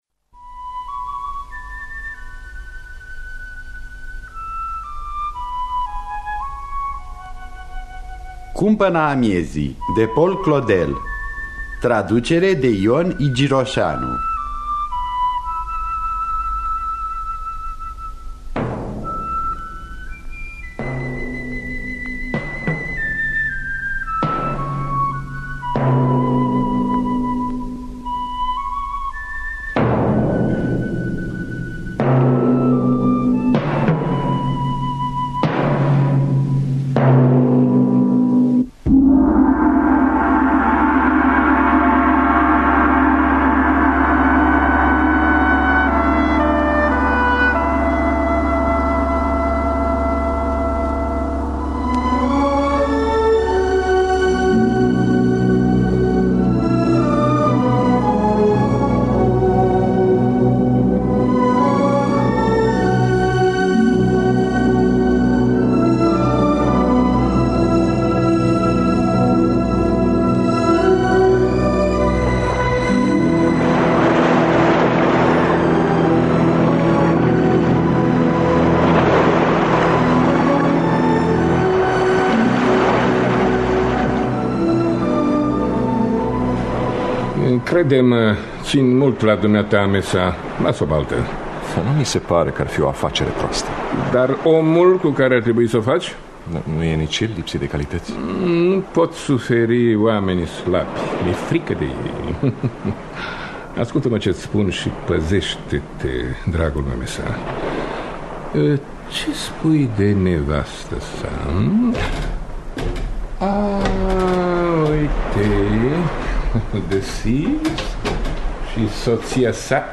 Cumpăna amiezii de Paul Claudel – Teatru Radiofonic Online